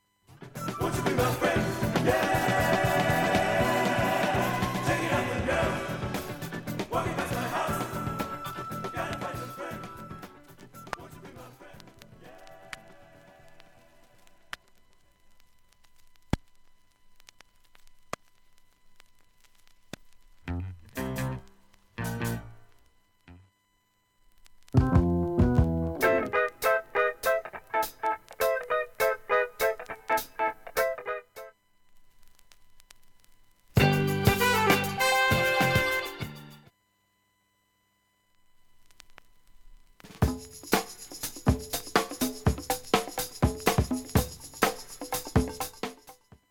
普通に音質良好全曲試聴済み。
現物の試聴（上記と曲間録音時間45秒）できます。音質目安にどうぞ
2回のかすかなプツが1箇所。
単発のかすかなプツが４箇所。